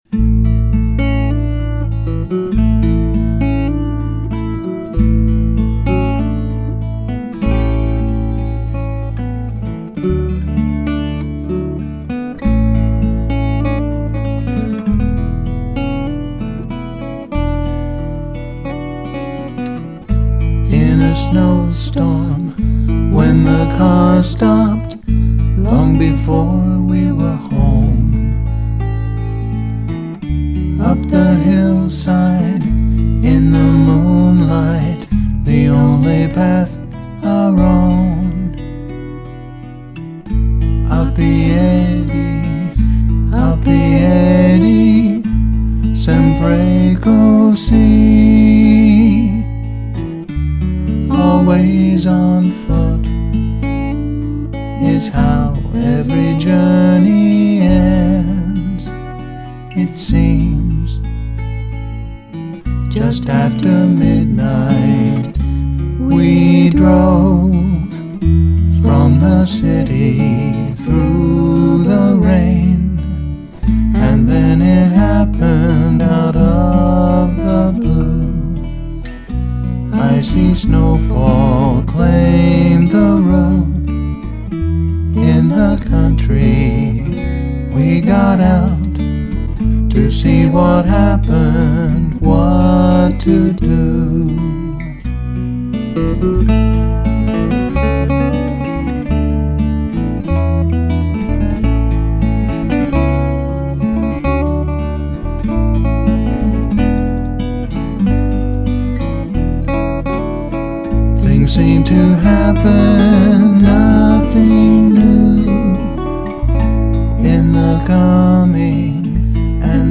guitar and vocals